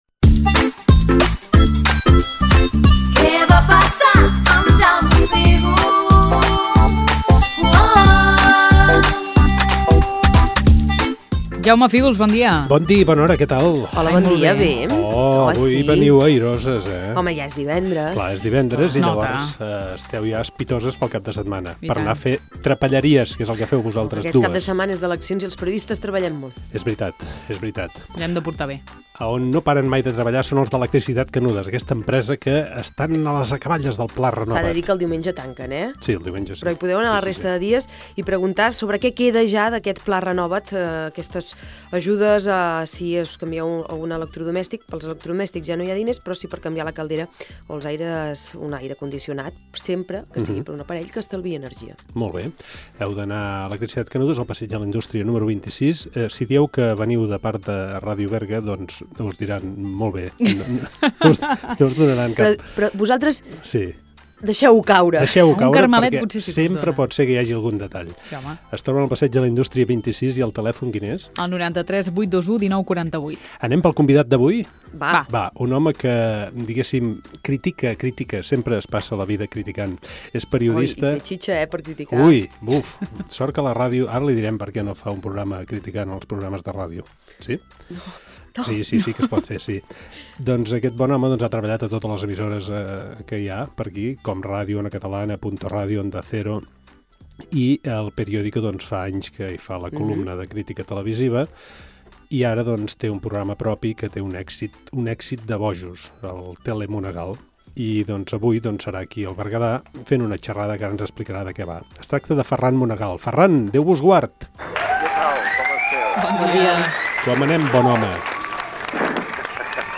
Pública municipal
Careta del programa, publicitat i entrevista telefònica al crític de televisió Ferran Monegal